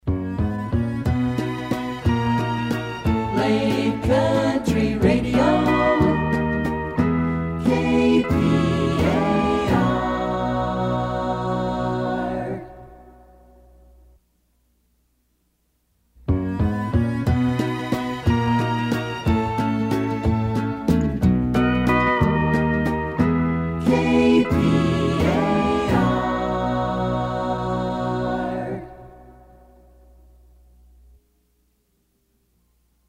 jingles
country music format